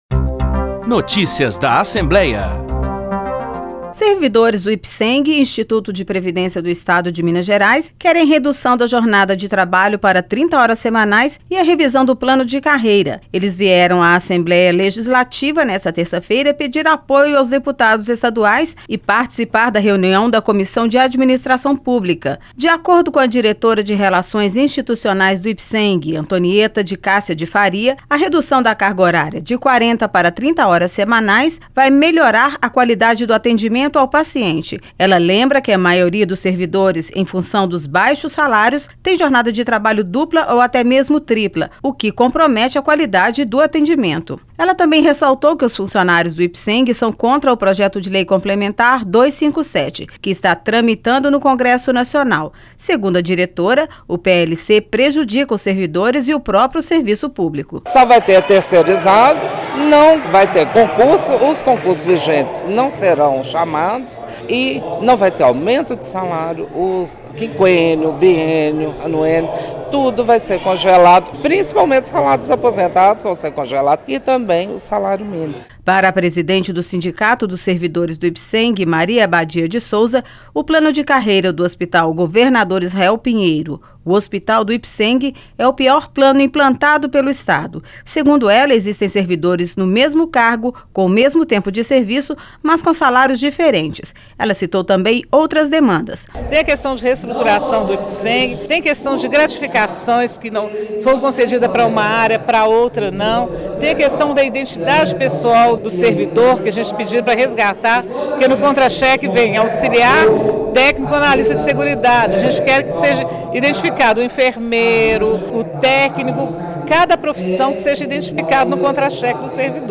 Durante audiência pública, eles também criticaram o PLC 257, que tramita no congresso Nacional e prevê contrapartida dos Estados para renegociarem suas dívidas com a União.